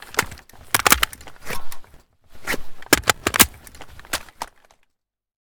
an94_reload.ogg